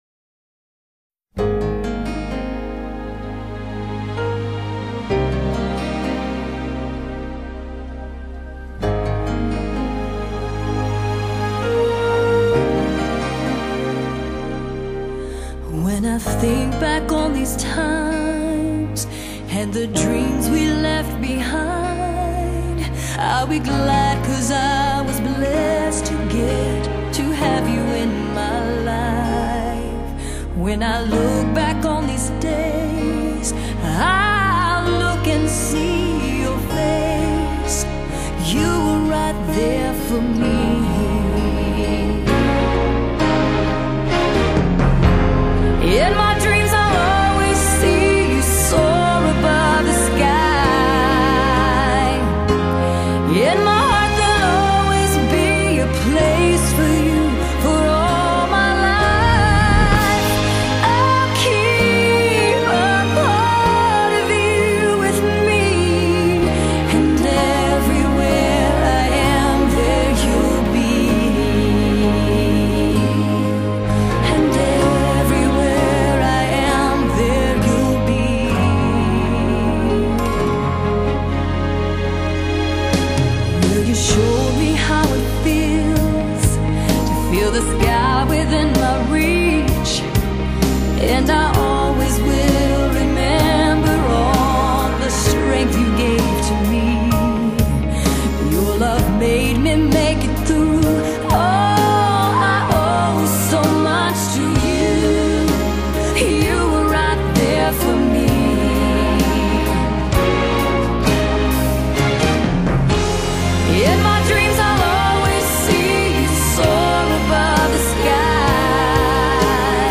电影主题曲
真情呼唤的歌声将一份永驻心头的依偎情谊自然涌现